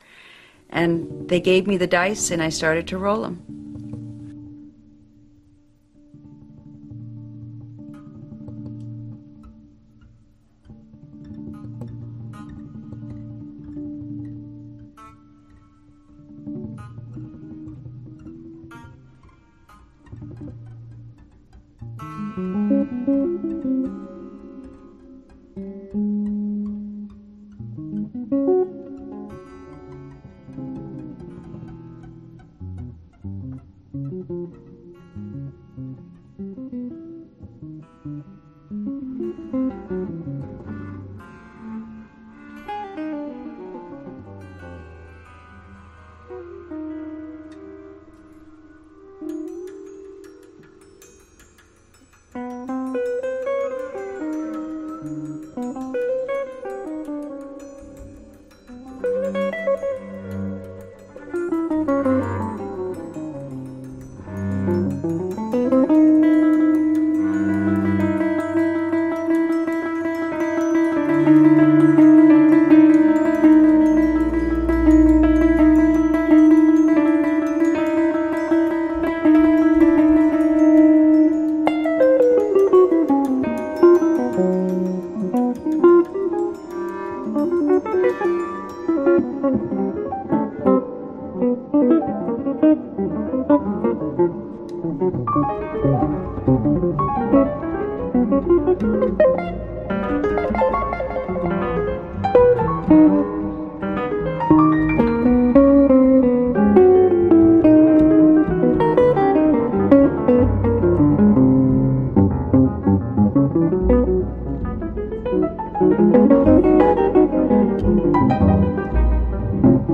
all combined to create a vibrant and sublime sonic puzzle